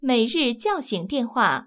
ivr-for_daily_wakeup_calls.wav